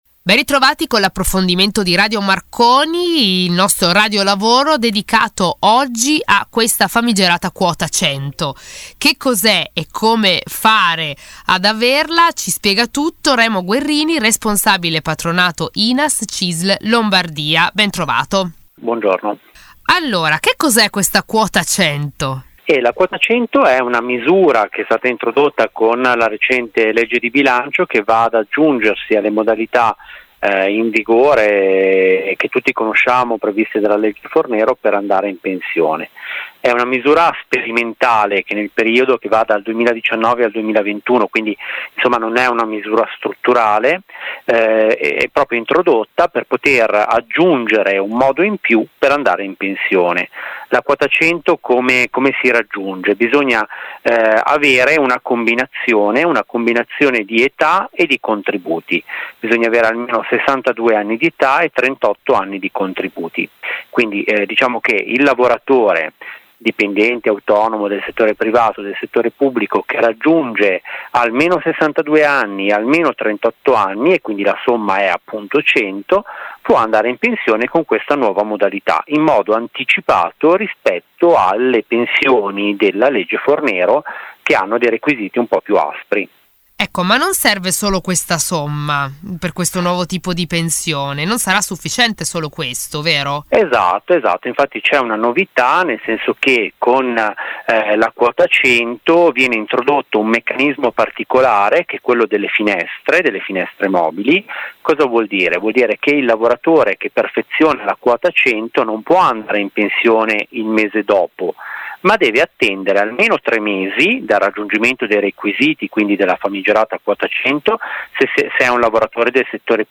Questa settimana intervista